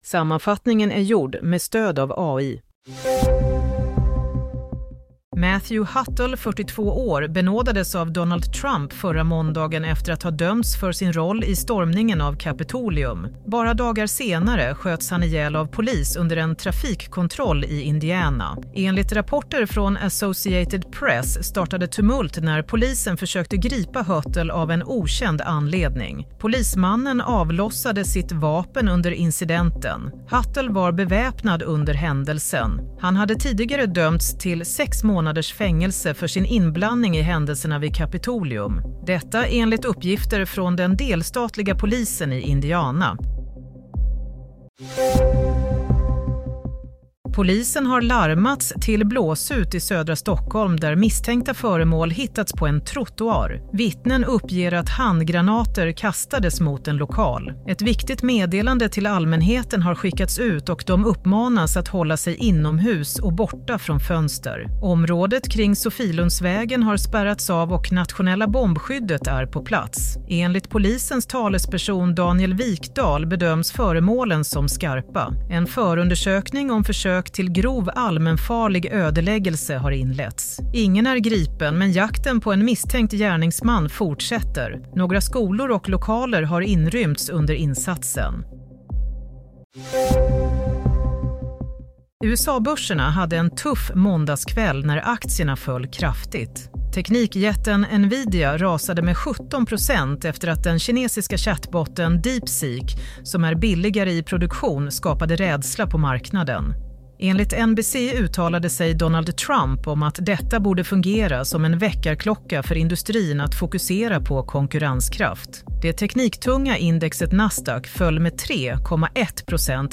Play - Nyhetssammanfattning - 28 januari 16.00